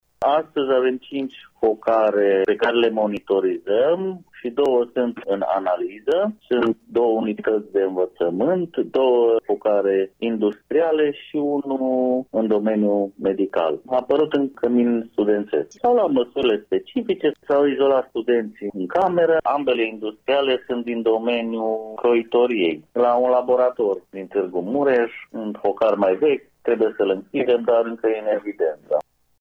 Mai multe detalii aflăm de la directorul Direcţiei Judeţene de Sănătate Publică, Iuliu Moldovan: